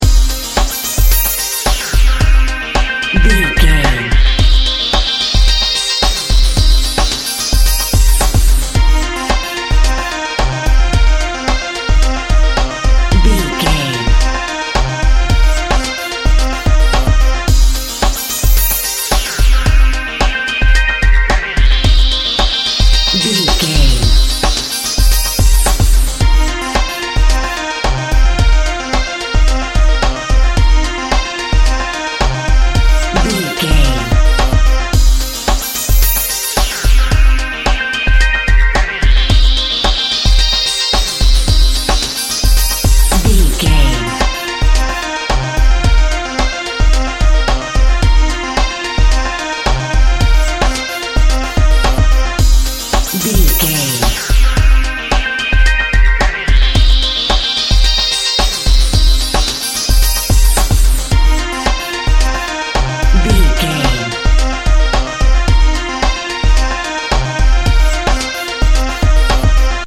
Ionian/Major
orchestral
dramatic
majestic
melodic
cinematic
hopeful
soaring
synths
synth lead
synth bass
synth drums